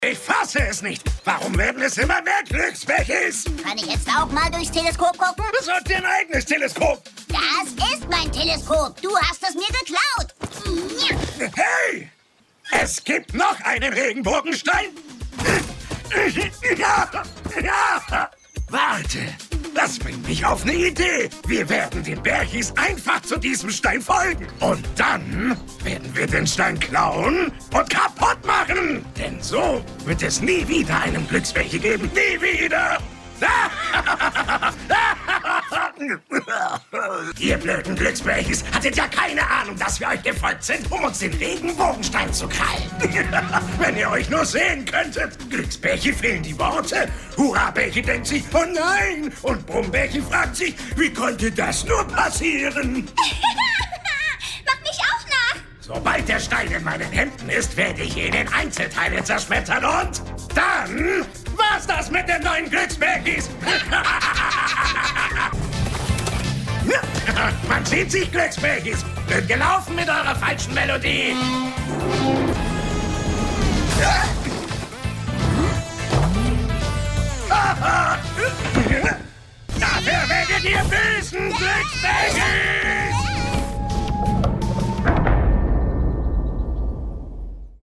markant, sehr variabel
Mittel plus (35-65)
Lip-Sync (Synchron)